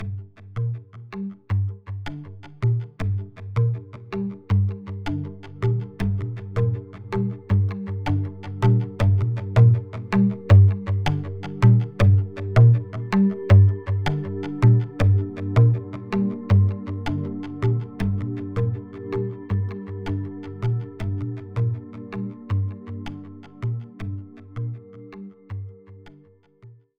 Intro Transition Sound Effect Free Download
Intro Transition